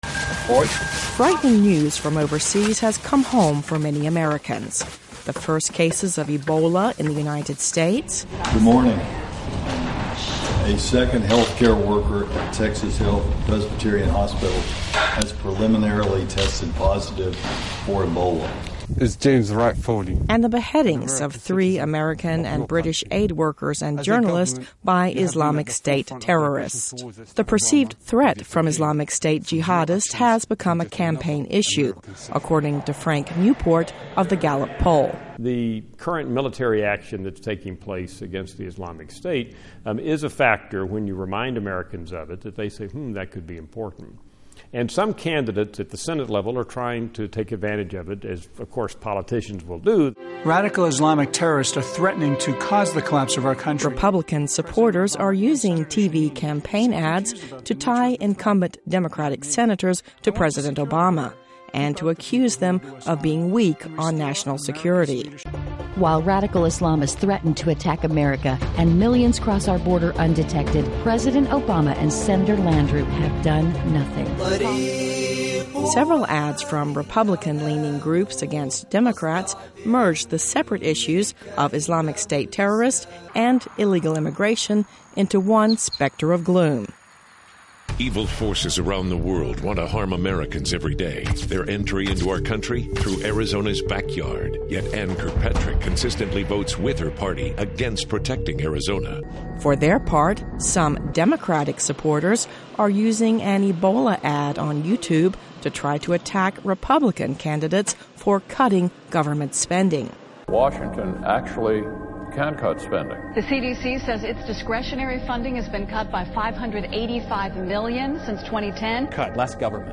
US Midterm Elections - Report